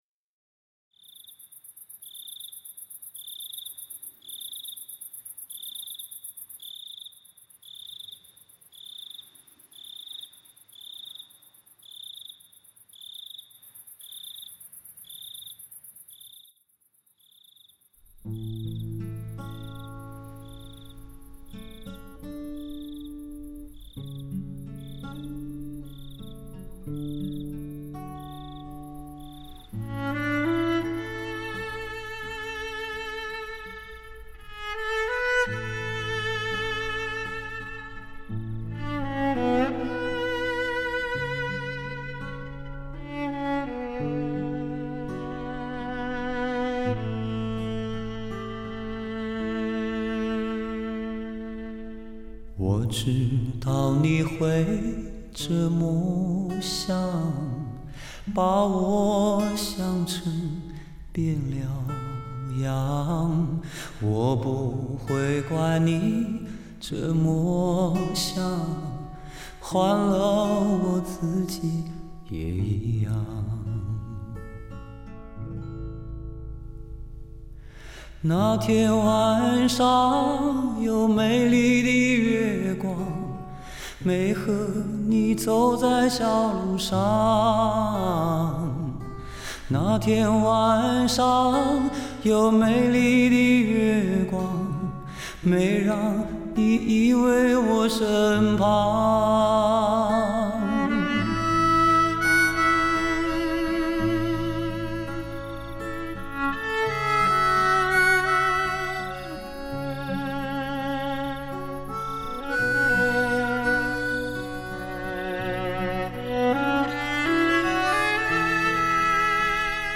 全面超越传统CD格式 营造360度音场效果，更细腻更纯正的音质 更清晰更纯净的音色。